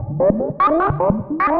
Index of /musicradar/rhythmic-inspiration-samples/150bpm
RI_ArpegiFex_150-04.wav